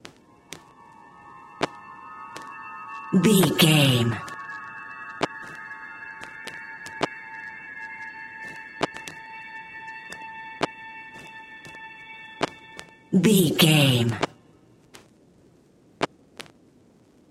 Short musical SFX for videos and games.,
Sound Effects
Epic / Action
Fast paced
In-crescendo
Uplifting
Ionian/Major
aggressive
bright
intense
bouncy
energetic
funky
heavy